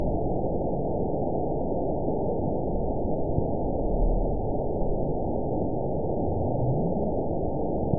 event 921923 date 12/22/24 time 20:57:31 GMT (11 months, 2 weeks ago) score 9.56 location TSS-AB04 detected by nrw target species NRW annotations +NRW Spectrogram: Frequency (kHz) vs. Time (s) audio not available .wav